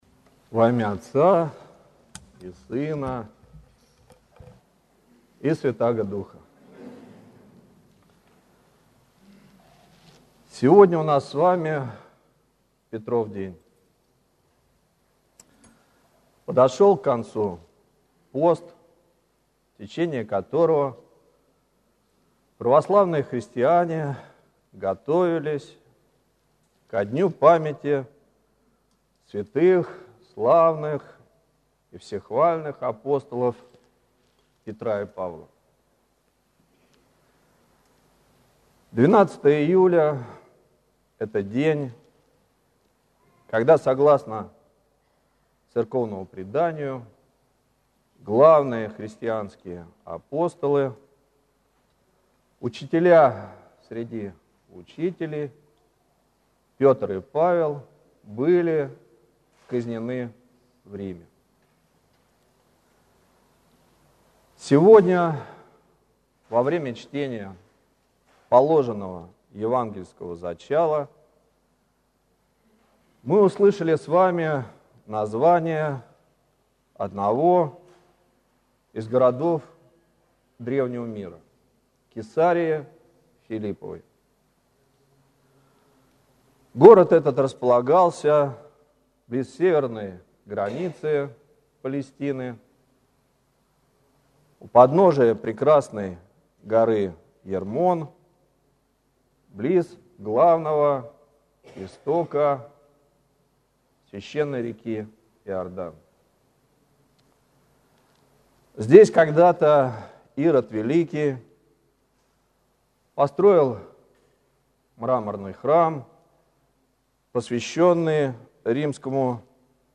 Слово в день памяти апостолов Петра и Павла